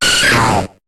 Cri de Kapoera dans Pokémon HOME.